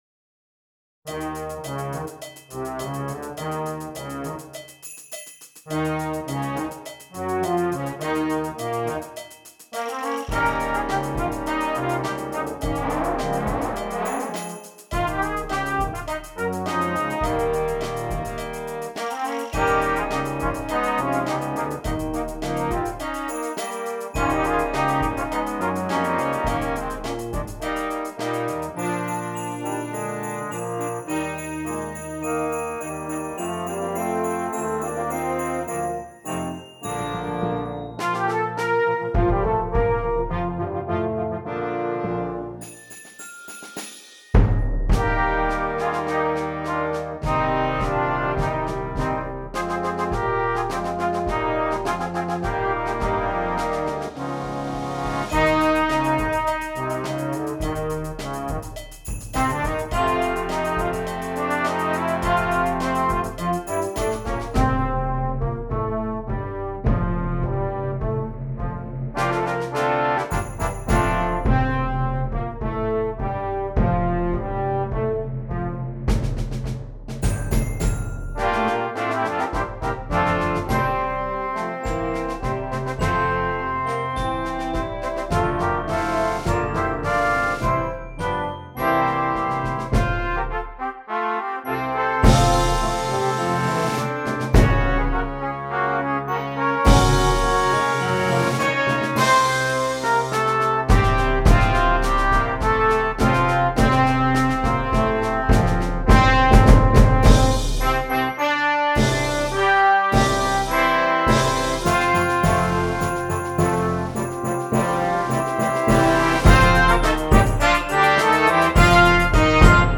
Christmas
Brass Choir
spicy and energetic take